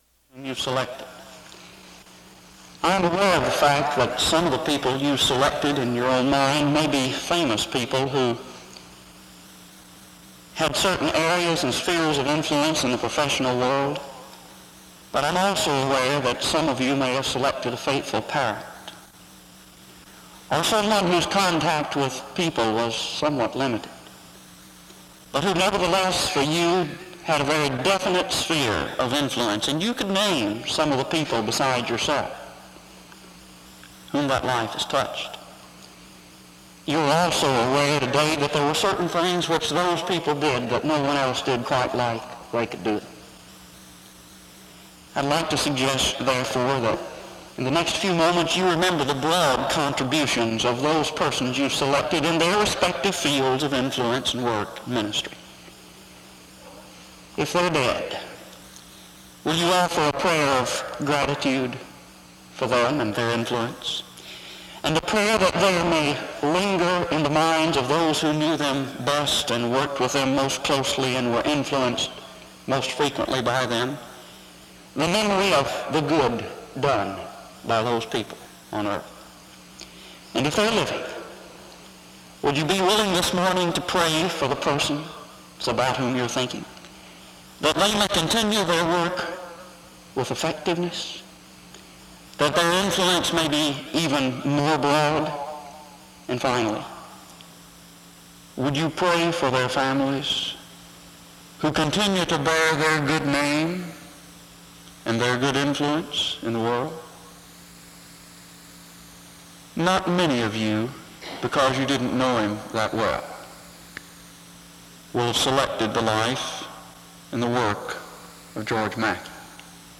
SEBTS Chapel
• Wake Forest (N.C.)